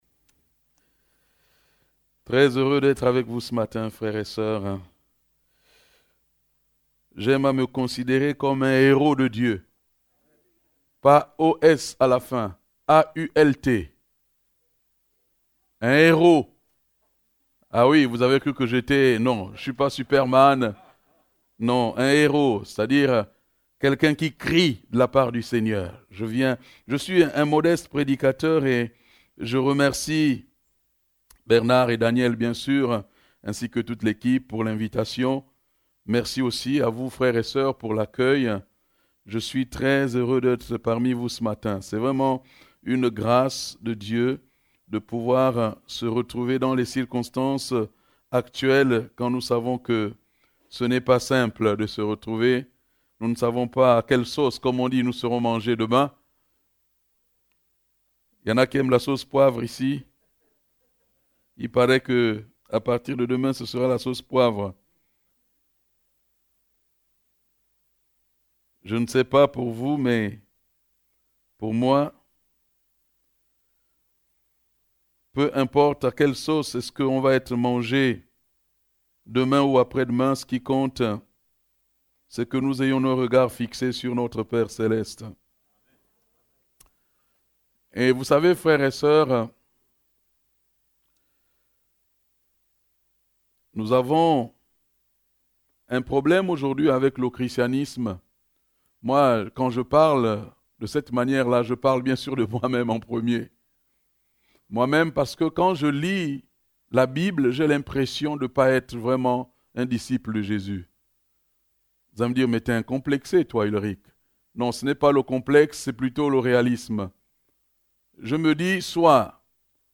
Passage: Matthieu 12 Type De Service: Culte « Jésus te connaît bien plus que tu ne le penses Que vivent les chrétiens persécutés ?